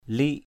/li:ʔ/ (đg.) vấn, lăn = rouler (avec les mains ou les doigts). roll (with hands or fingers). lik pakaw l{K pk| vấn thuốc = rouler une cigarette. rolling a cigarette....